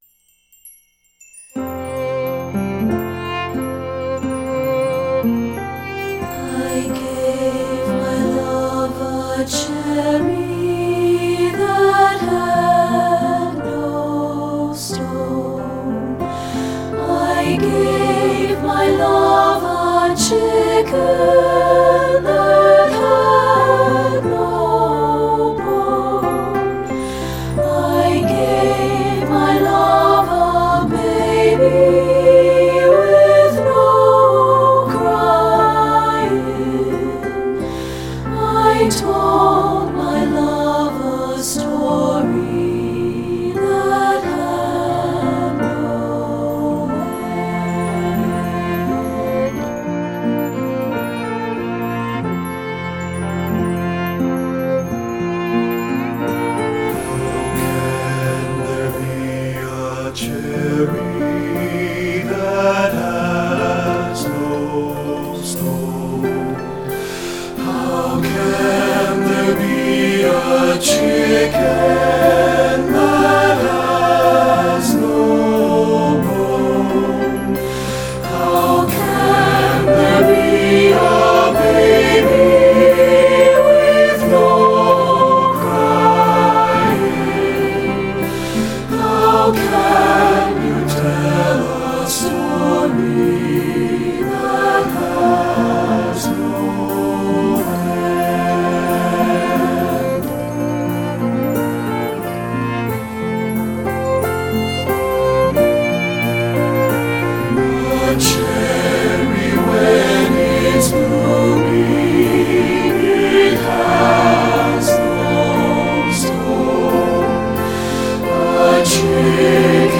secular choral
SATB recording